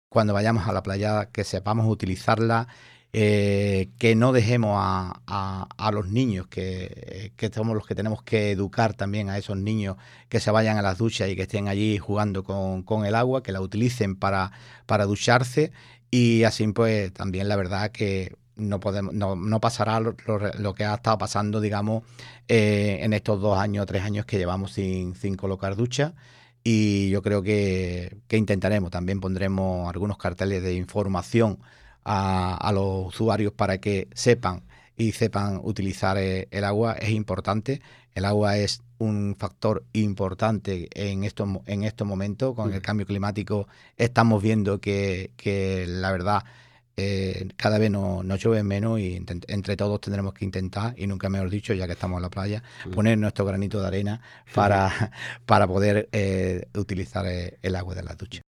Canal San Roque Radio contó en los estudios con el teniente de alcalde Juan Serván, quien ostenta varias delegaciones en el Ayuntamiento y es responsable de la tenencia de Alcaldía de la Zona Interior, para dar a conocer el trabajo que se realiza día a día en los departamentos que dirige, así como novedades en algunas de estas áreas municipales.